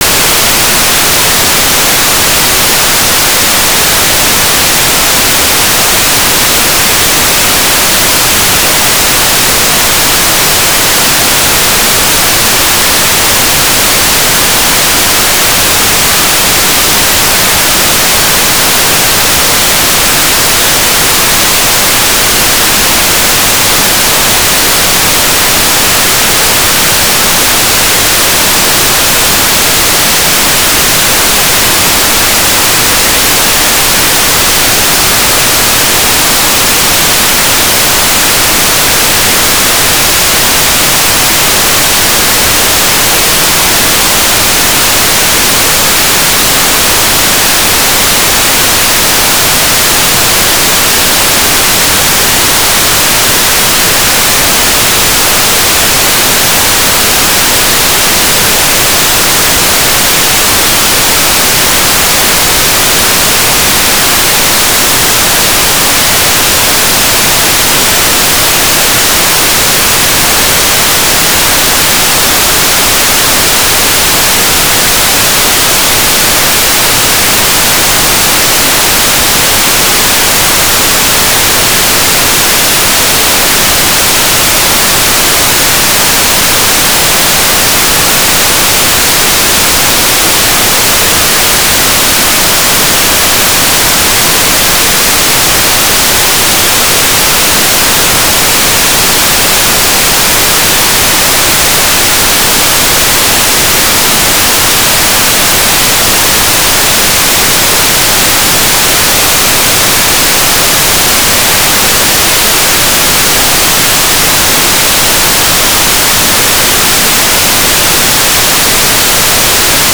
"transmitter_description": "Mode V/V - APRS digipeater",
"transmitter_mode": "AFSK",